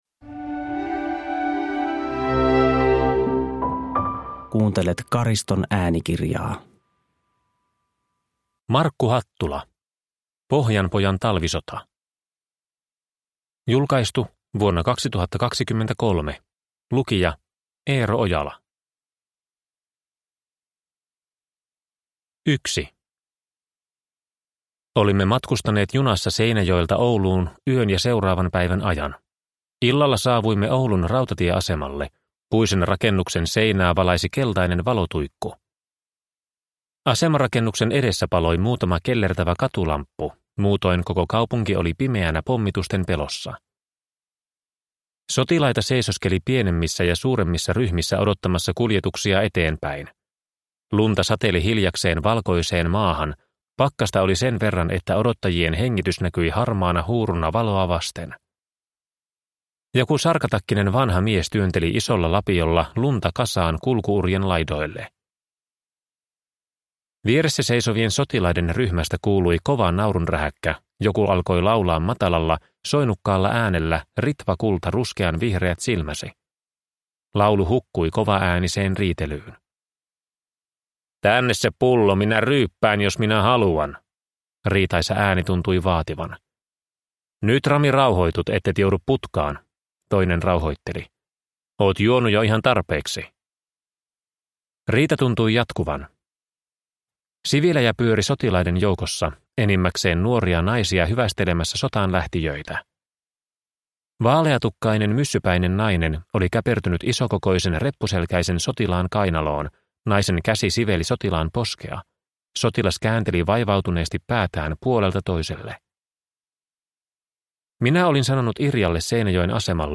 Pohjanpojan talvisota – Ljudbok – Laddas ner